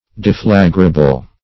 Search Result for " deflagrable" : The Collaborative International Dictionary of English v.0.48: Deflagrable \De*fla"gra*ble\ (?; 277), a. [See Deflagrate .]